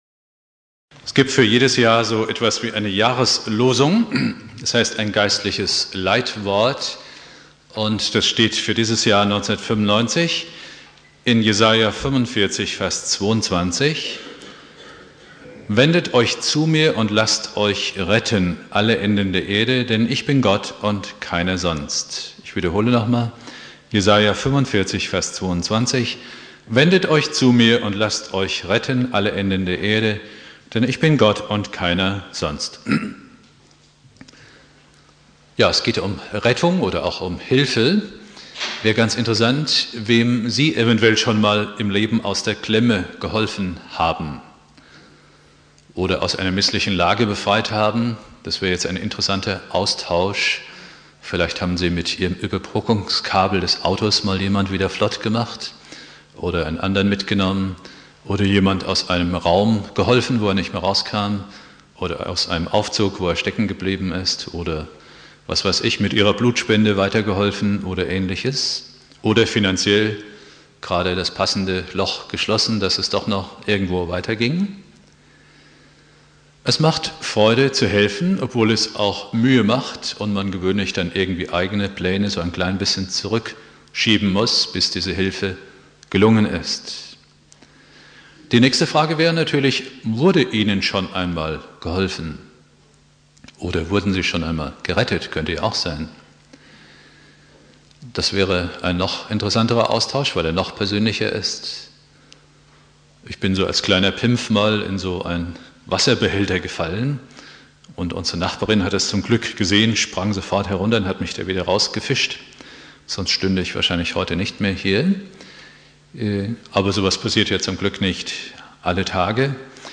Predigt
Neujahr Prediger